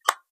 switch11.wav